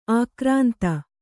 ♪ ākrānta